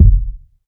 KICK.47.NEPT.wav